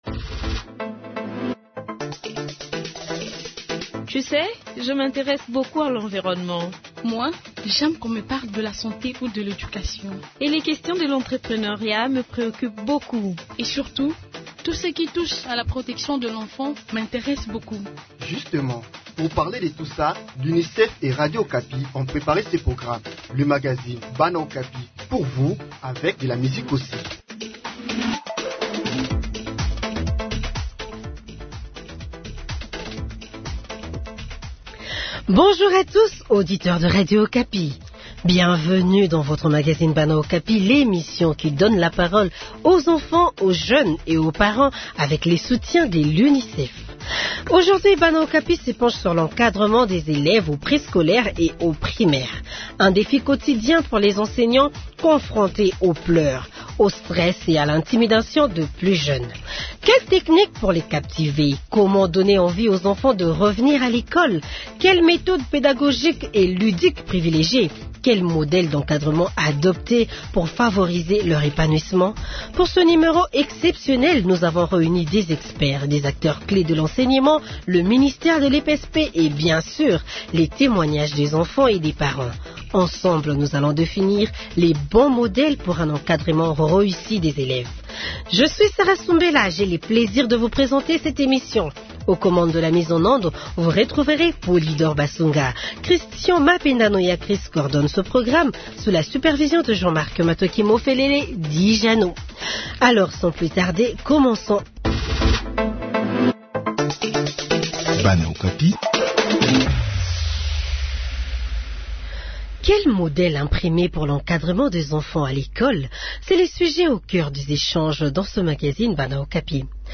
Pour ce numéro exceptionnel, nous avons réuni des experts, des acteurs clés de l'enseignement, le ministère de l'EPSP, et bien sûr, les témoignages des enfants et des parents. Ensemble, nous allons définir le bon modèle pour un encadrement réussi des élèves.